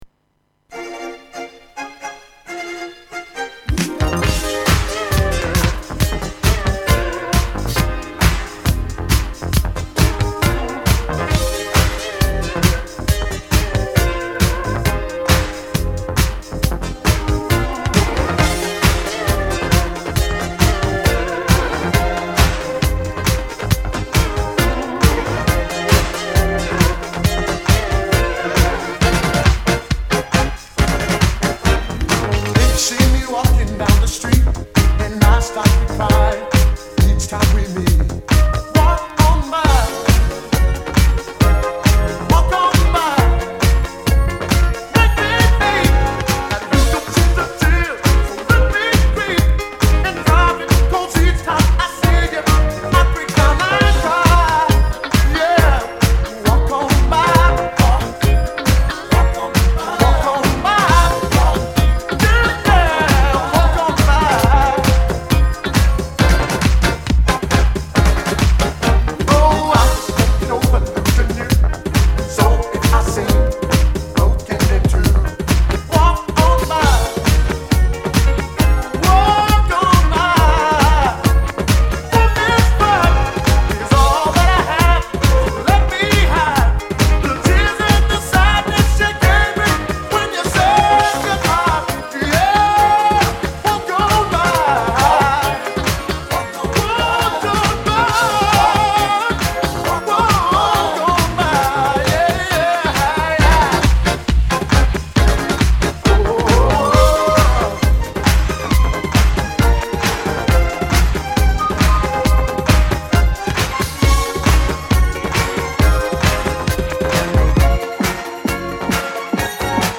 voici un set move-aimant�.
R�tromix
Rockmix
housy